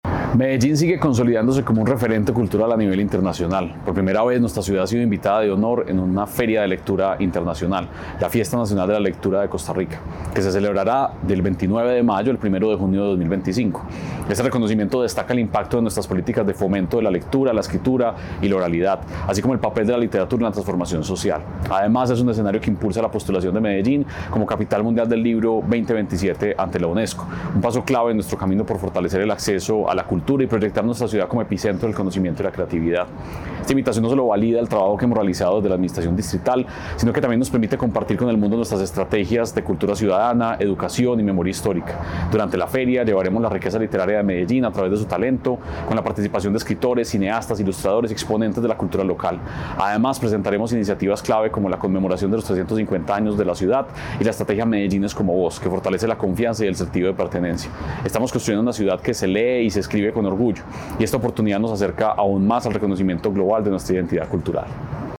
Audio Palabras de Santiago Silva Jaramillo, secretario de Cultura Ciudadana Medellín sigue destacándose como epicentro internacional de la cultura, la literatura y la creatividad.